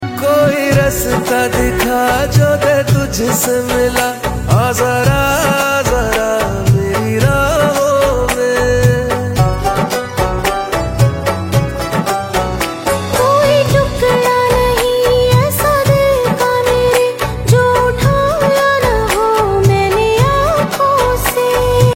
Catchy Beats and Memorable Lyrics